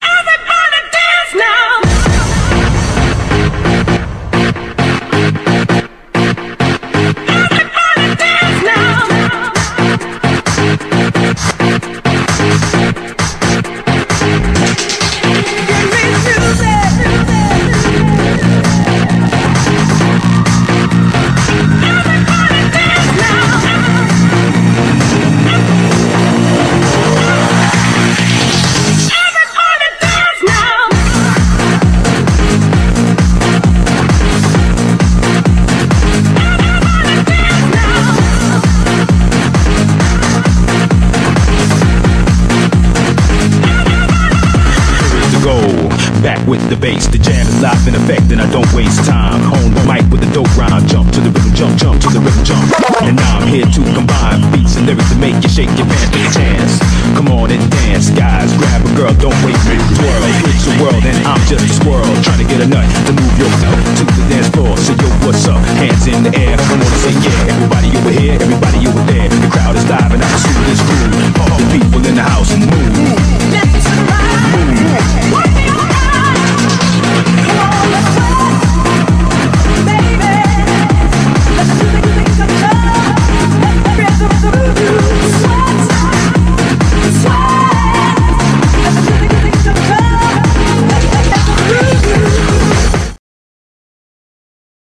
BPM132
Audio QualityMusic Cut